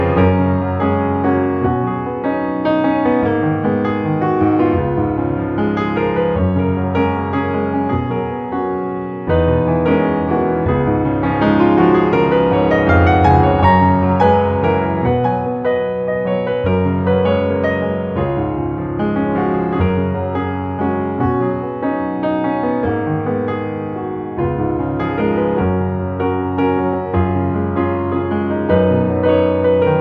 14 original, easy listening piano solos.